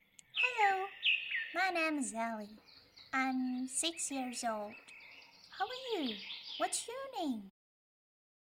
Могу озвучивать детей (как мальчиков,так и девочек), подростков, молодых и зрелых девушек.
Микрофон Samson C01U Pro, компьютер Macbook, специальное помещение для записи